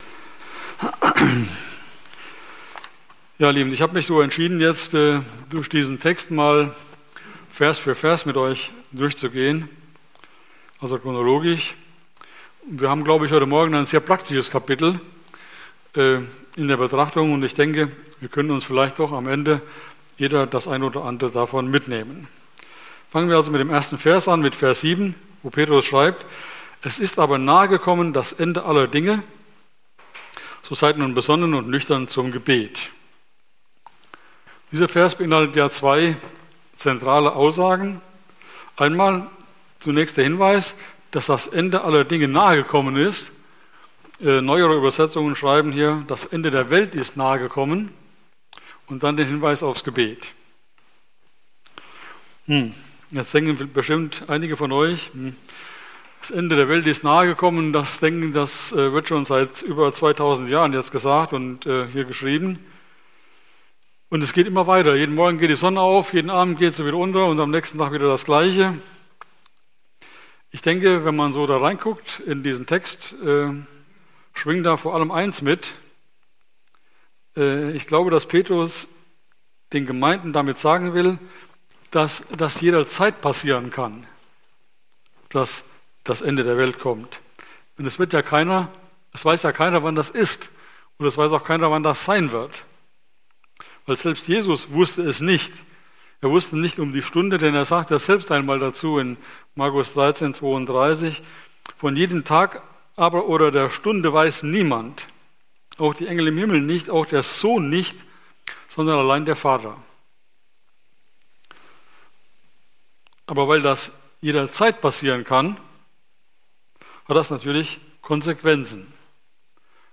02.06.2024 Wortbetrachtung ~ Predigten - FeG Steinbach Podcast